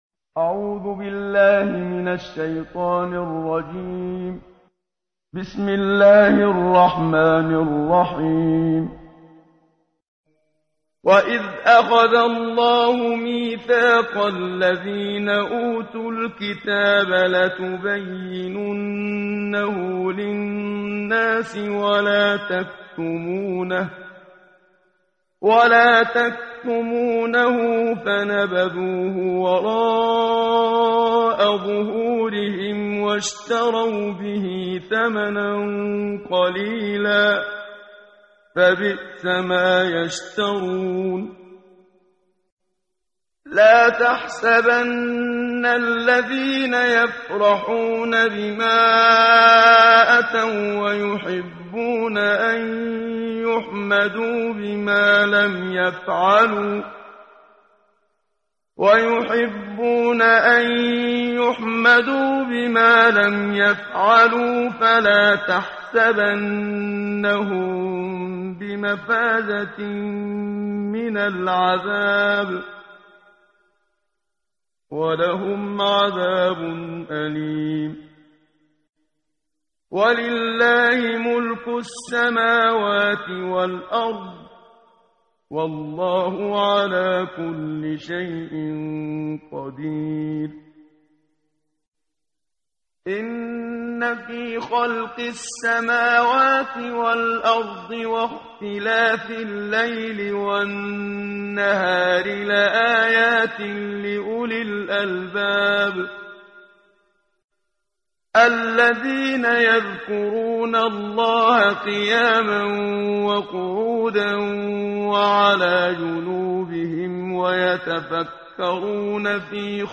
قرائت قرآن کریم ، صفحه 75، سوره مبارکه آلِ عِمرَان آیه 187 تا 194 با صدای استاد صدیق منشاوی.